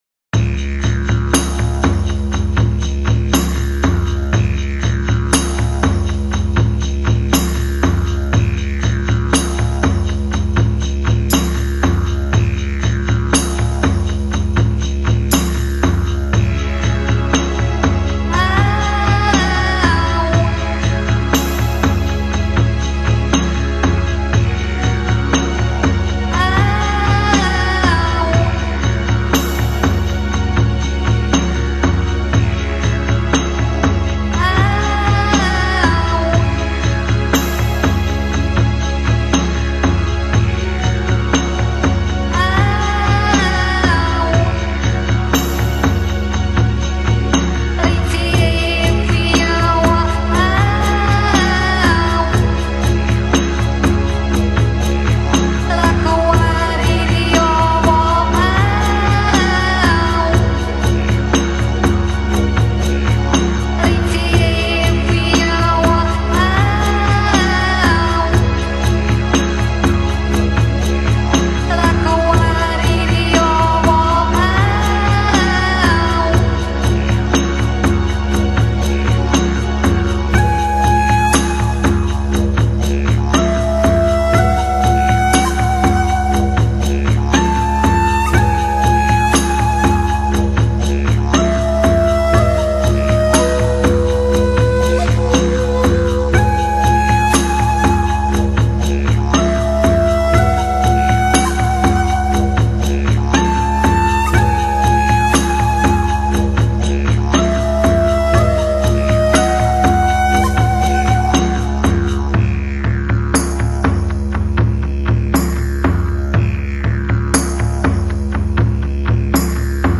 音乐类型: Newage
架子鼓的振响，在森林里回荡。土著女人厚沉的声线，揉织成清晨的赞歌，
寂寞的歌声，遥应尺八，送一路祈祷，企盼日落的那一刻，男人能够平安归来。
has a more Australian sound and Aboriginal chants.